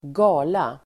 Uttal: [²g'a:la]